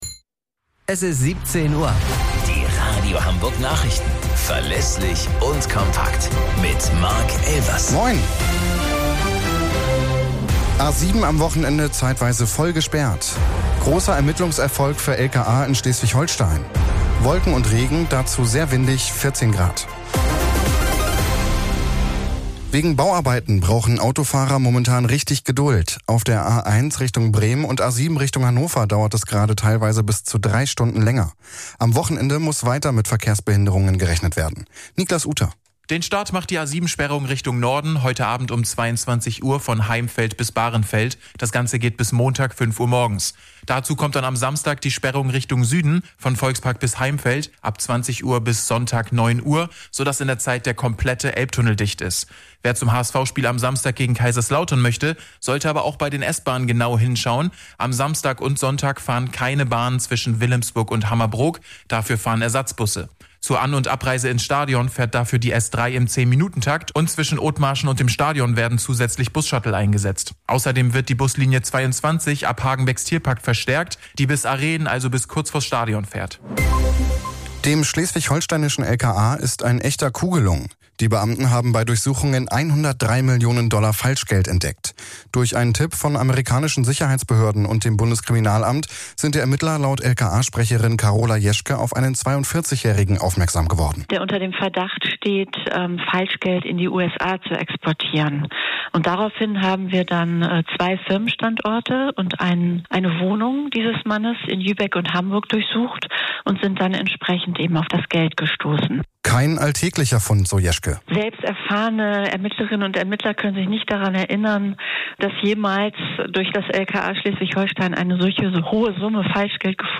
Radio Hamburg Nachrichten vom 05.06.2024 um 05 Uhr - 05.06.2024